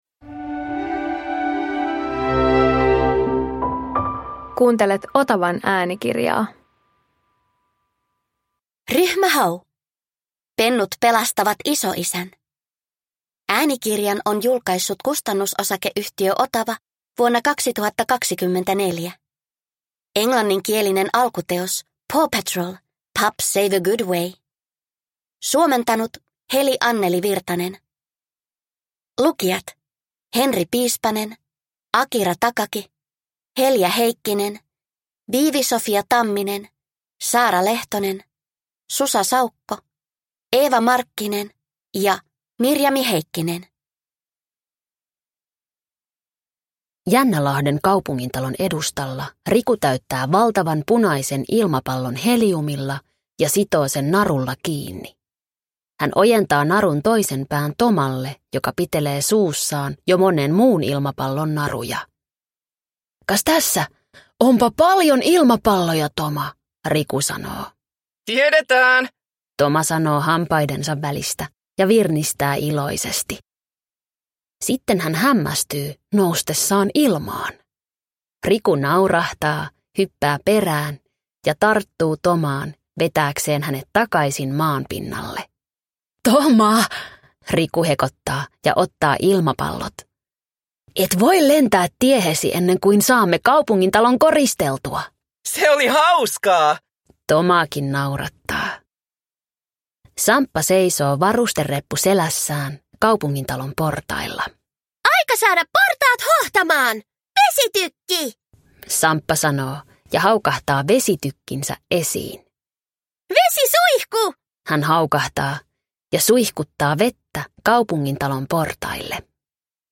Ryhmä Hau - Pennut pelastavat isoisän – Ljudbok